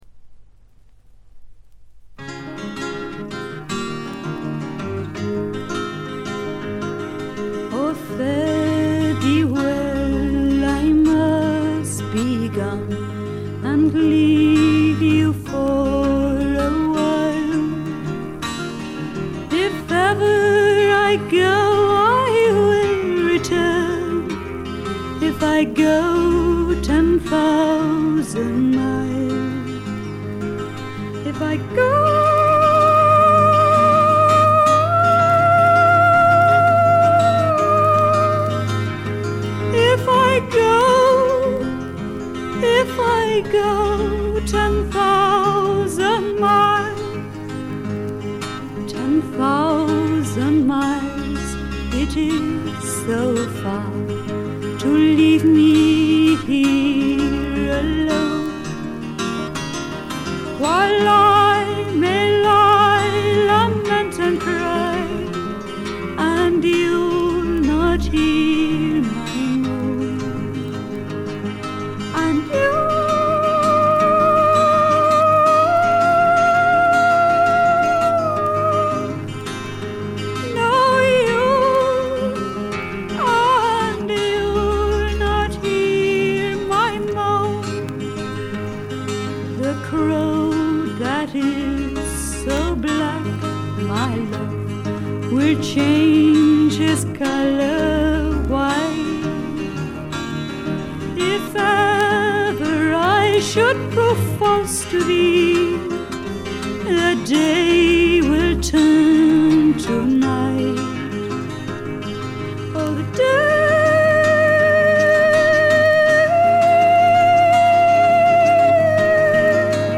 全体に軽微なバックグラウンドノイズ。
英国フィメール・フォークの大名作でもあります。
内容はというとほとんどがトラディショナル・ソングで、シンプルなアレンジに乗せた初々しい少女の息遣いがたまらない逸品です。
モノラル盤です。
試聴曲は現品からの取り込み音源です。